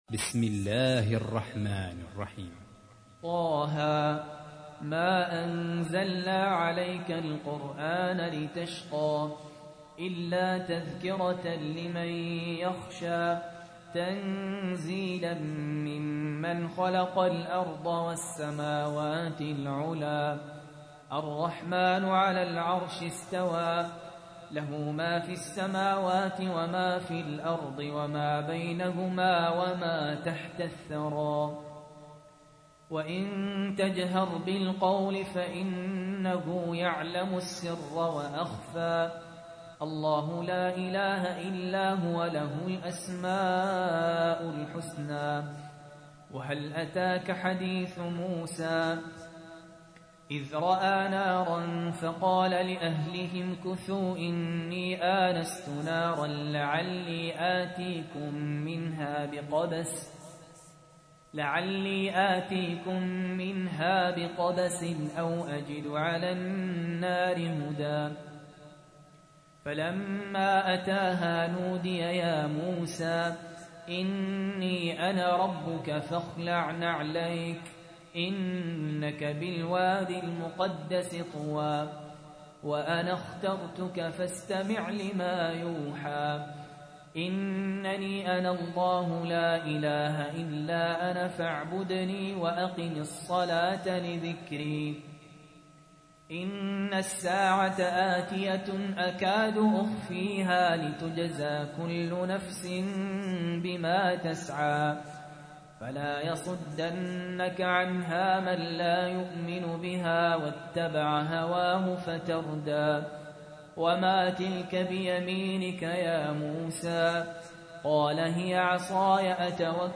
تحميل : 20. سورة طه / القارئ سهل ياسين / القرآن الكريم / موقع يا حسين